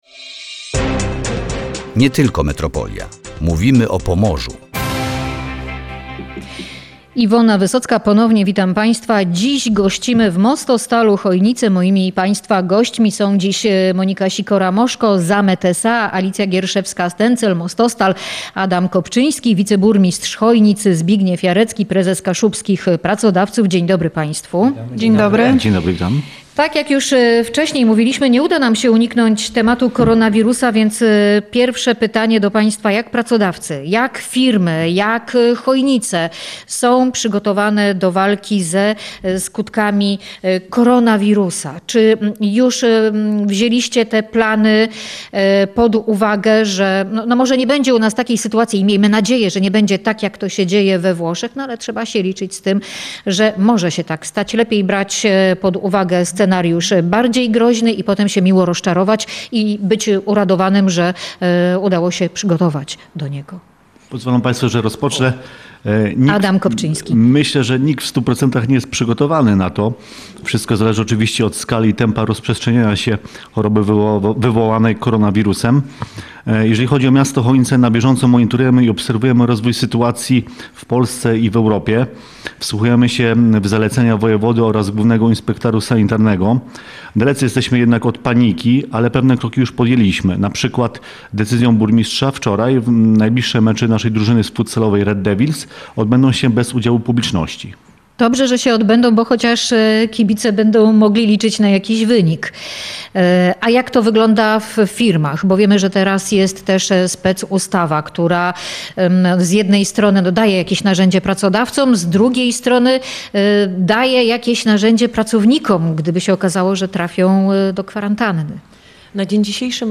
W Polsce na razie jest spokojnie, ale wszystkie miasta, gminy, przedsiębiorstwa i ochrona zdrowia przygotowują się na skutki koronawirusa. O przygotowaniach firm i miast rozmawialiśmy w audycji Nie tylko Metropolia. Wśród tematów było też szkolnictwo zawodowe i brak pracowników oraz inwestycje w Chojnicach.